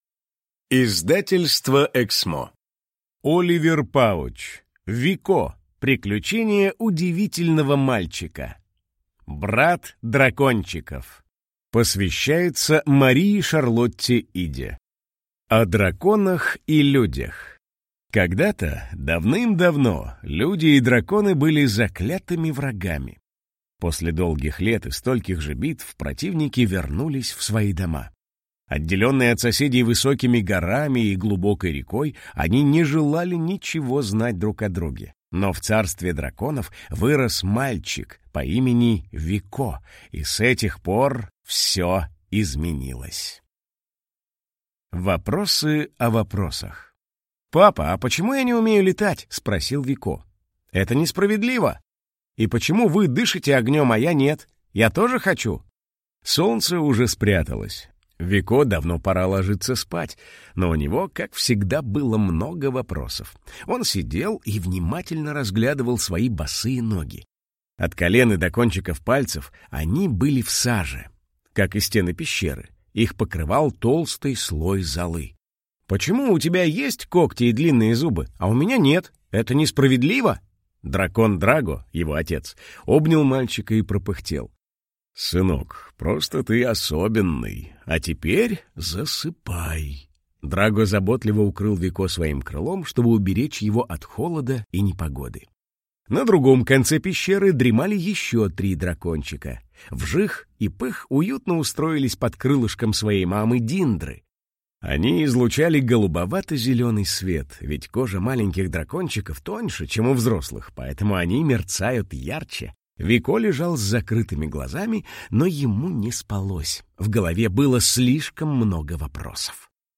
Аудиокнига Брат дракончиков | Библиотека аудиокниг